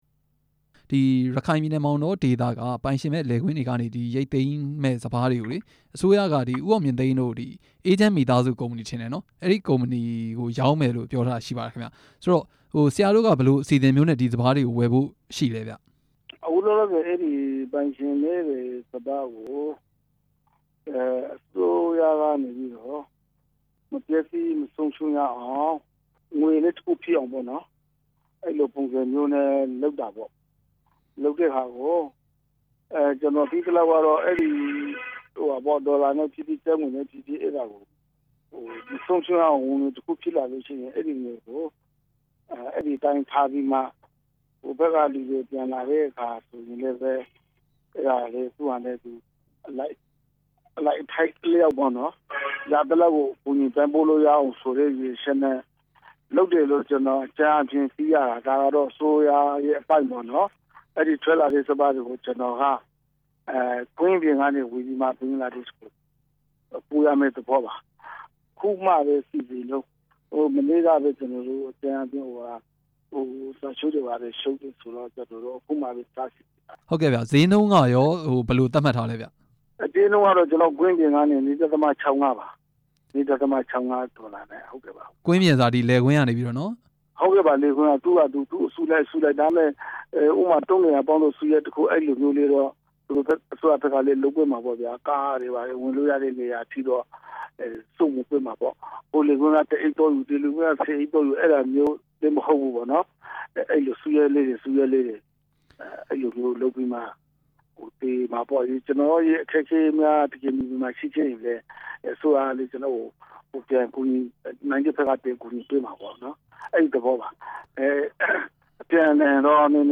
မောင်တောက ပိုင်ရှင်မဲ့ စပါးတွေ ရောင်းချမယ့်အကြောင်း မေးမြန်းချက်